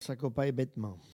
Collectif atelier de patois
Catégorie Locution